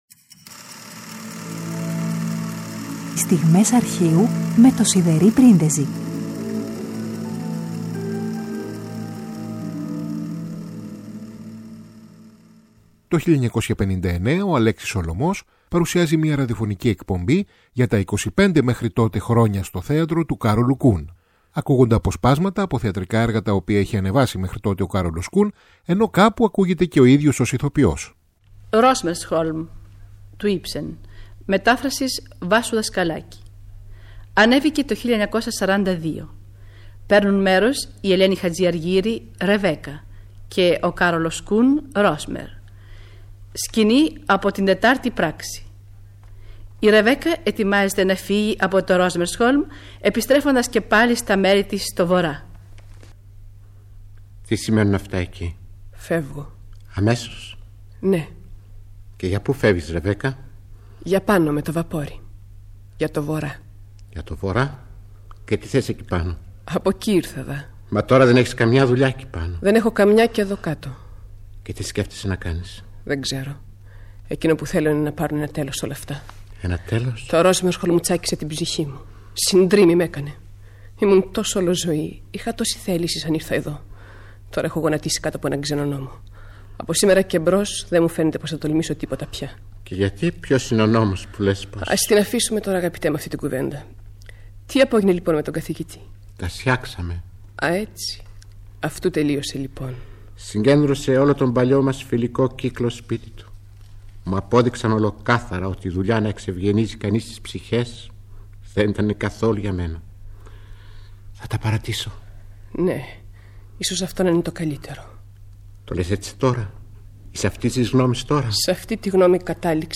Τετάρτη 16 Φεβρουαρίου: Το 1959 ο Αλέξης Σολομός ετοιμάζει μία ραδιοφωνική εκπομπή για τα 25 – τότε – χρόνια στο θέατρο του Κάρολου Κουν. Ακούγονται αποσπάσματα από θεατρικά έργα που είχε ανεβάσει μέχρι τότε ο Κάρολος Κουν, ενώ κάπου ακούγεται και ο ίδιος ως ηθοποιός. Μαζί του στο απόσπασμα από το έργο «Ρόσμερσχολμ» του Ίψεν, η Ελένη Χατζηαργύρη.